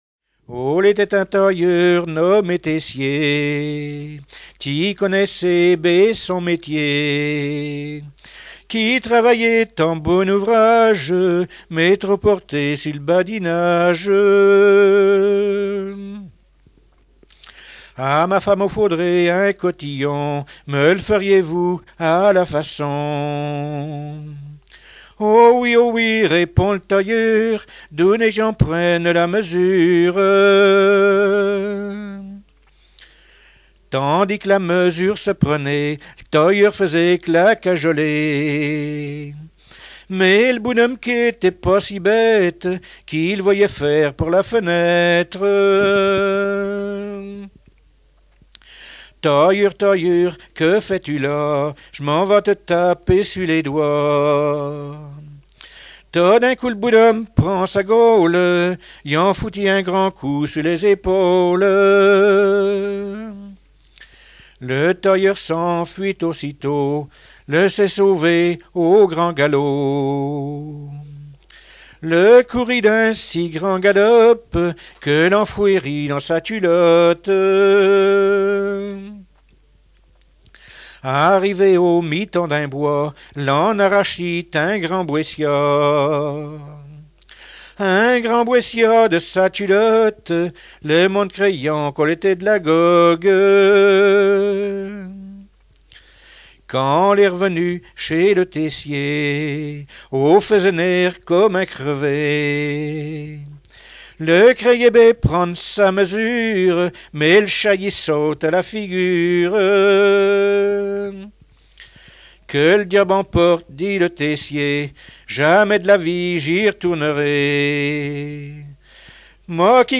Genre laisse
Fonds Arexcpo en Vendée